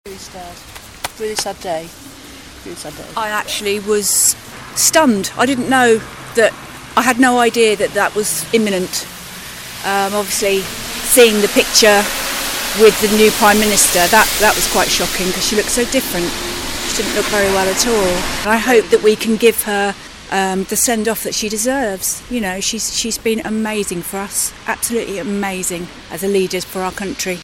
people in Kingston reflect on the Queen's passing